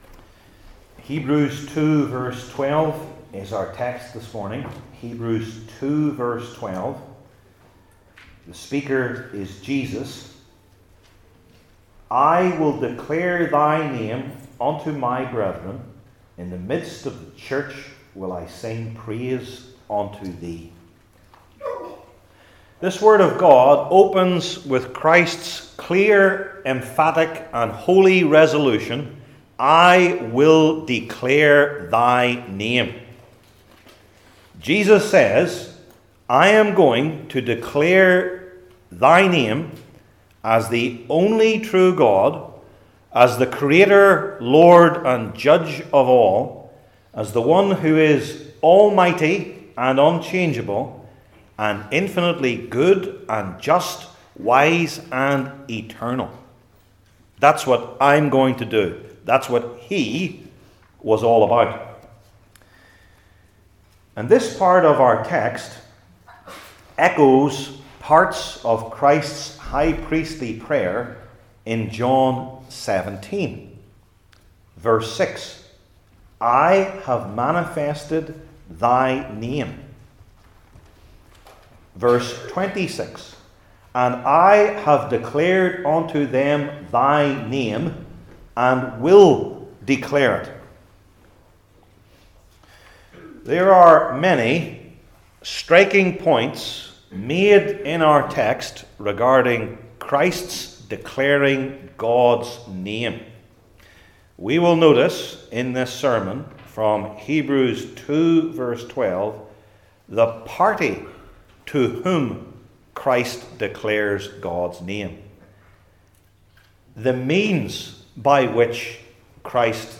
New Testament Sermon Series I. Unto Whom?